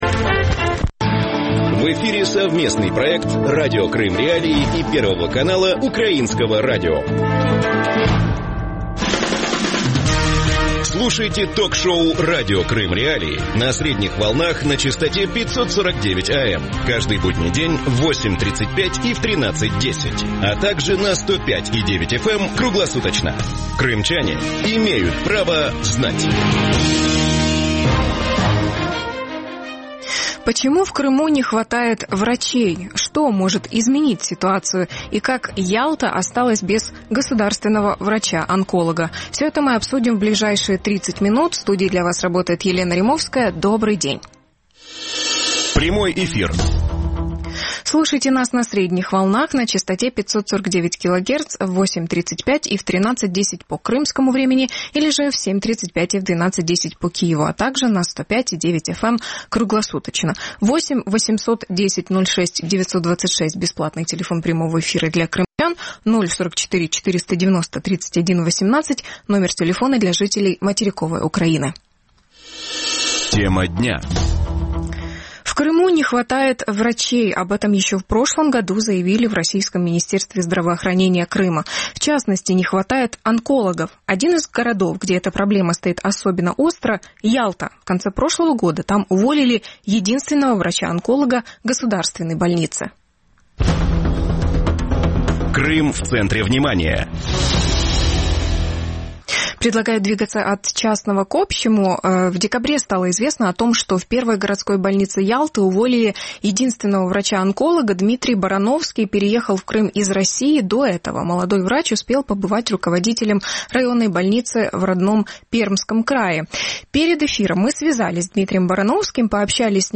Почему в Крыму не решается проблема нехватки врачей? На что жалуются крымчане при получении медицинских услуг? Гости эфира: